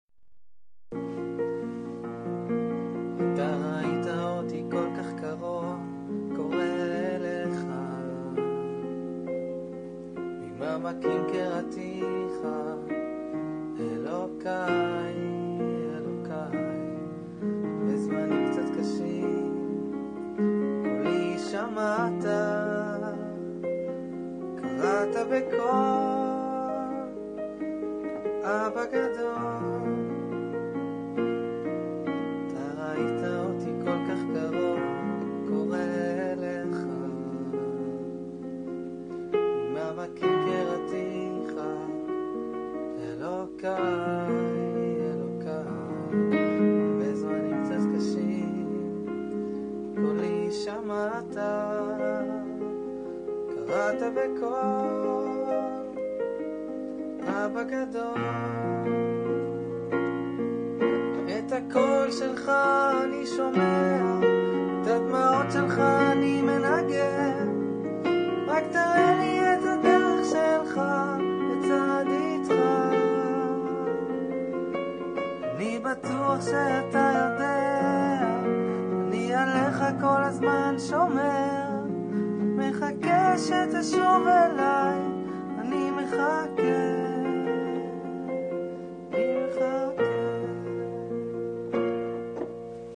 נגינה בפסנתר